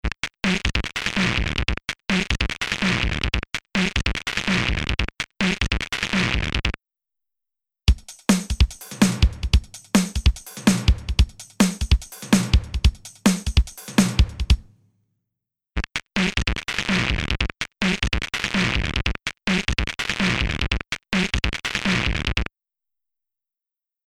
パワフルなディストーション＆サチュレーション・エンジン
CrushStation | Drum Machine | Preset: Destroyed Bit Kit
CrushStation-Eventide-Drum-Machine-Destroyed-Bit-Kit.mp3